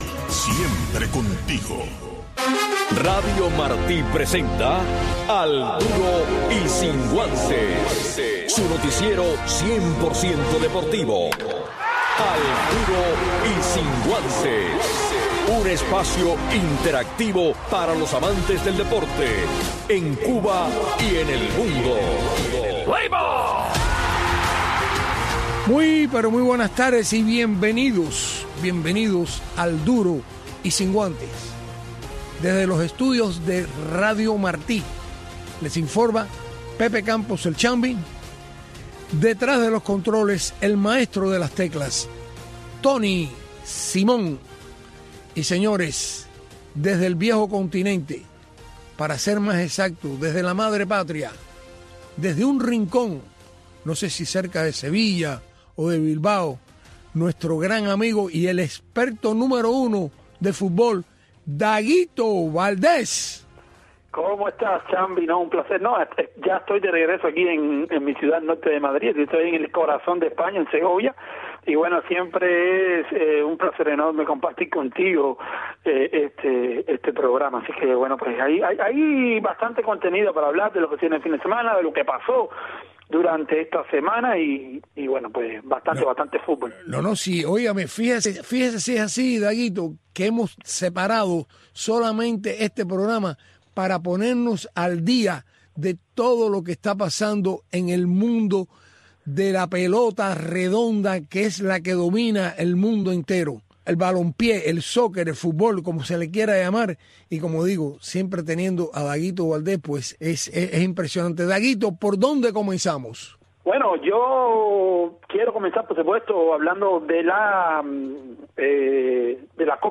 Un resumen deportivo en 60 minutos conducido por